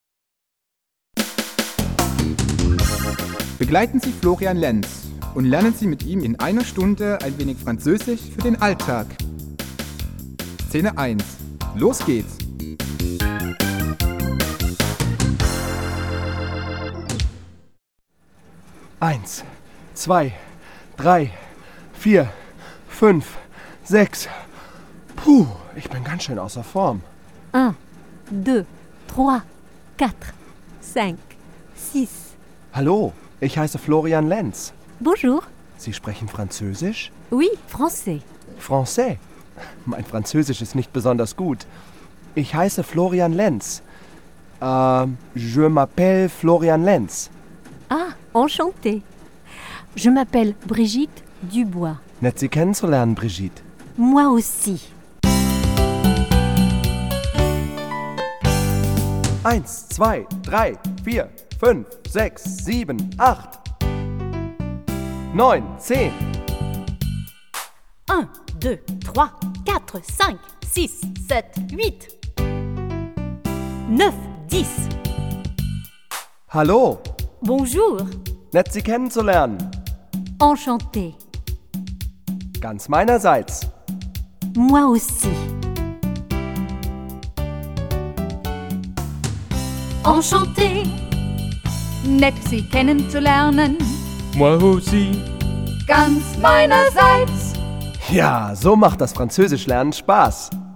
Ein musikalisches Sprachtraining